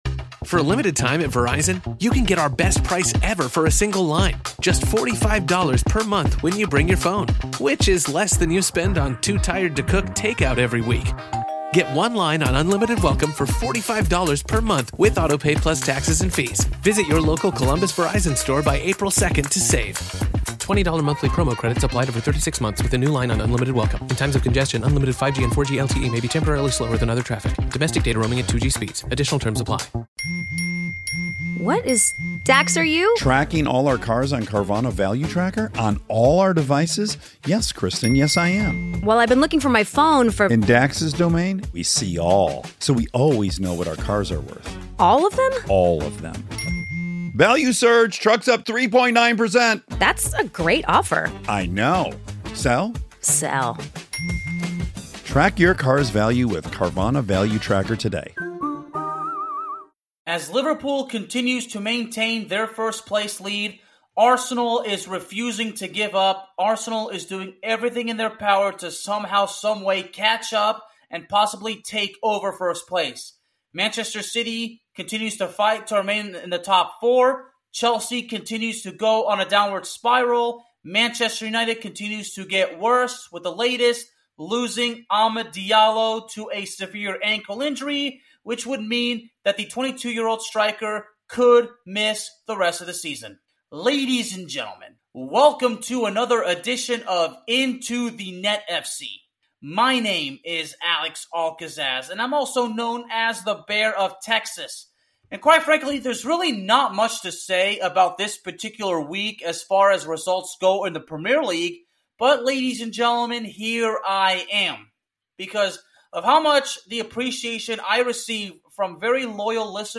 The episode is filled with insights, statistics, and passionate commentary on the beautiful game.